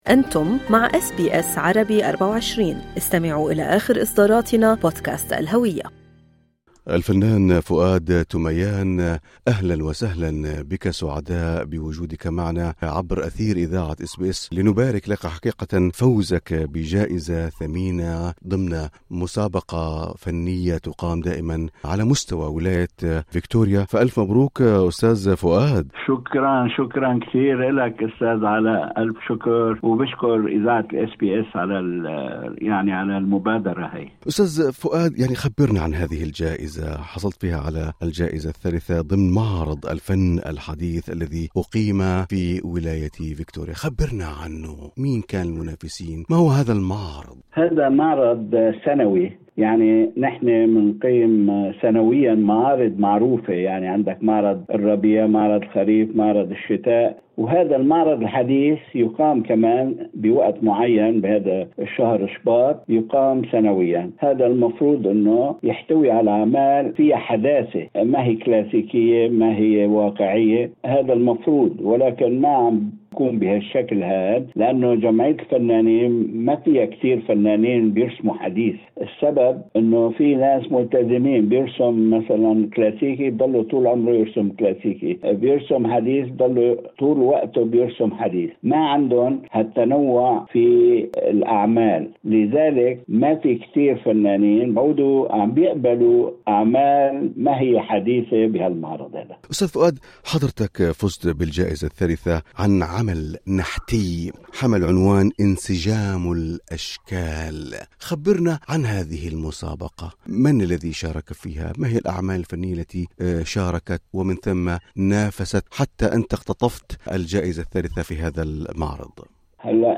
في لقاء حصري عبر أثير إذاعة أس بي اس عربي